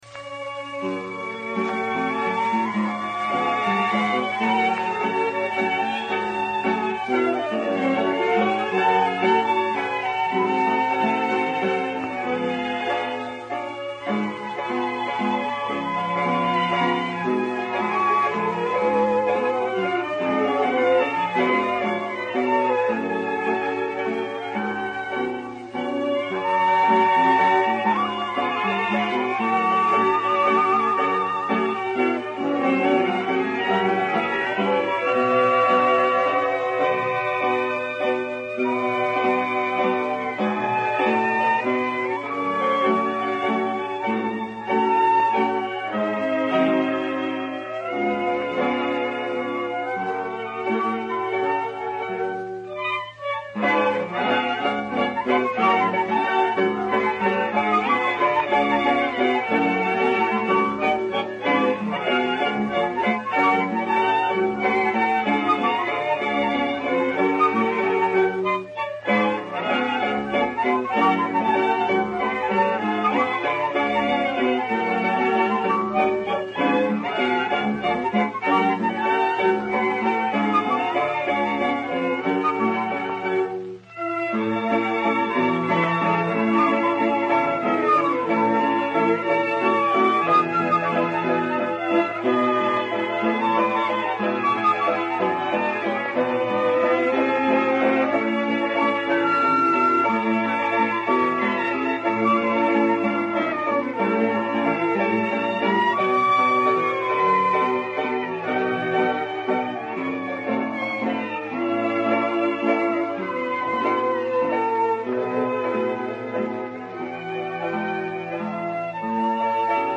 versão instrumental